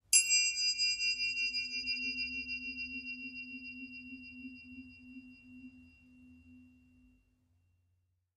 Triangle Sml Strike Spins 2